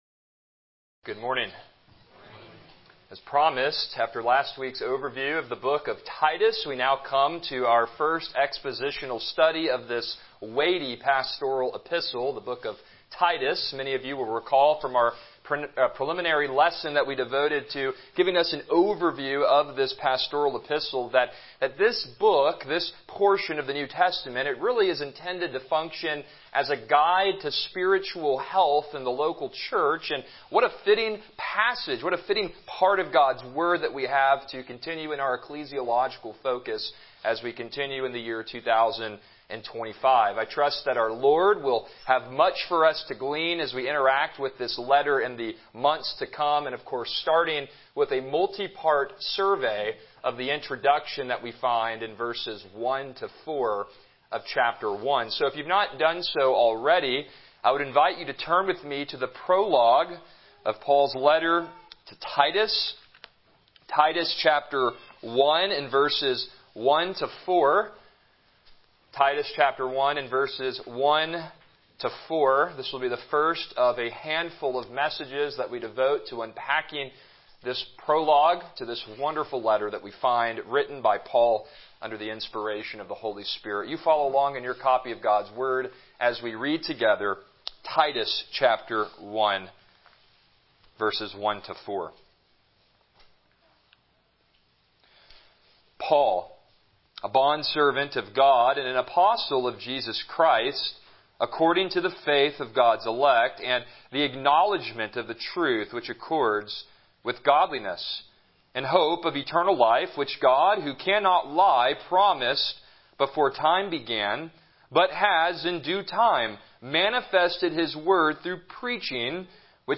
Passage: Titus 1:1a Service Type: Morning Worship